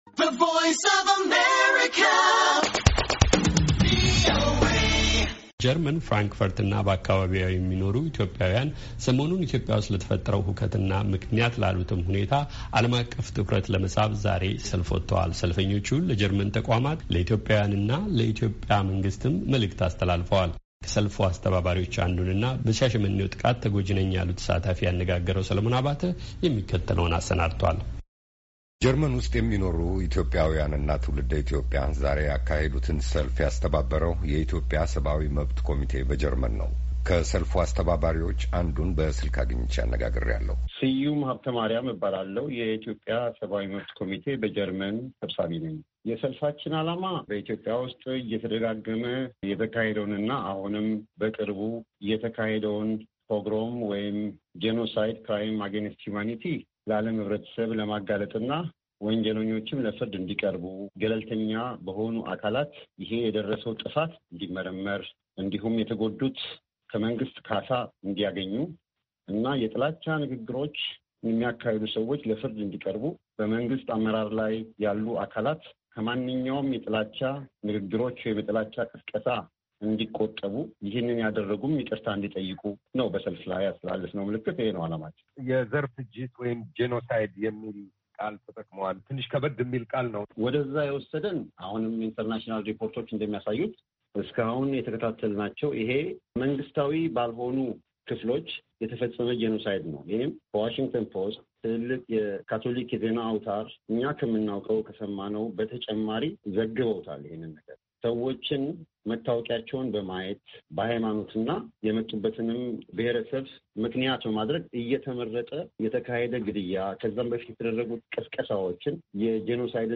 ሰልፈኞቹ ለጀርመን ተቋማት፣ ለኢትዮጵያዊያንና ለኢትዮጵያ መንግሥትም መልዕክት አስተላልፈዋል። ከሰልፉ አስተባባሪዎች አንዱንና በሻሸመኔው ጥቃት ተጎጂ ነኝ ያሉ ተሣታፊ አነጋግረናል።